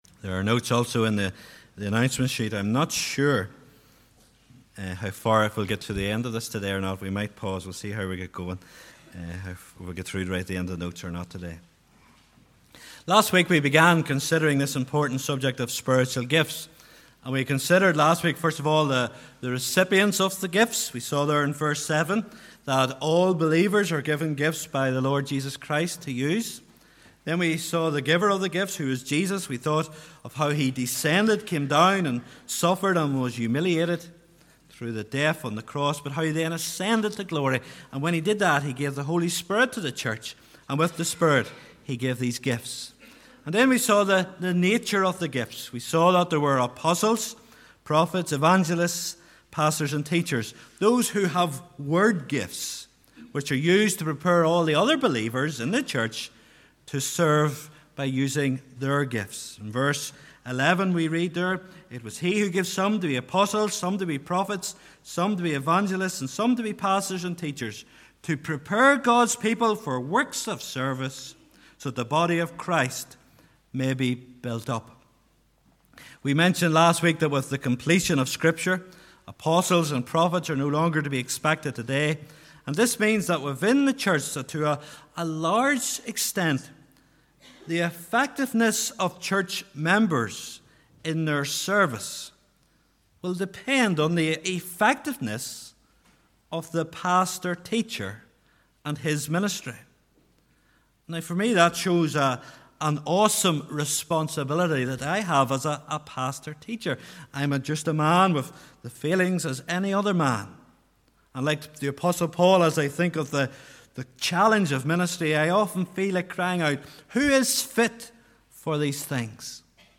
Listen to sermon audio. Ephesians 4:11–16